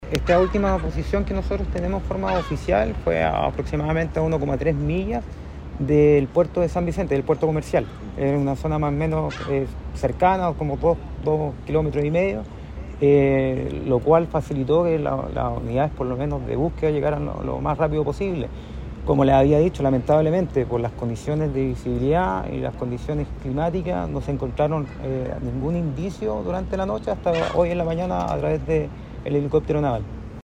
Díaz precisó que se perdió contacto con la nave cerca de las 20.30 horas de ayer y la última posición de la embarcación artesanal fue a aproximadamente 2,6 kilómetros del puerto de San Vicente.